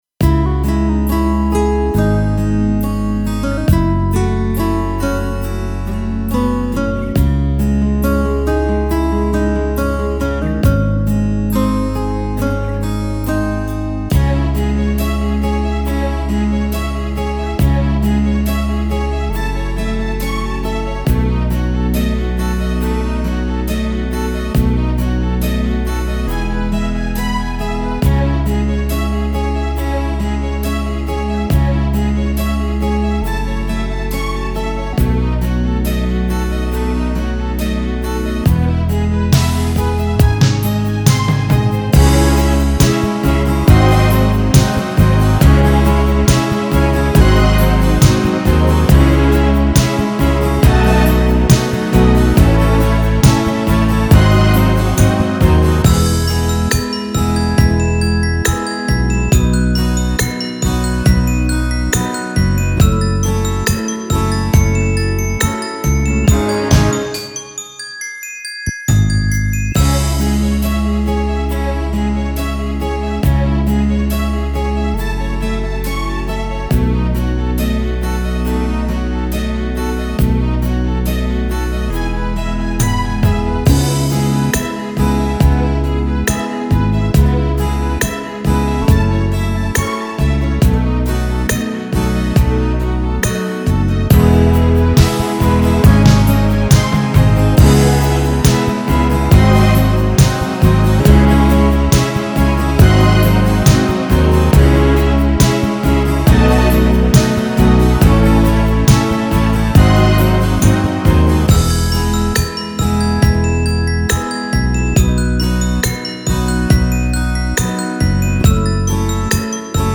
Украинские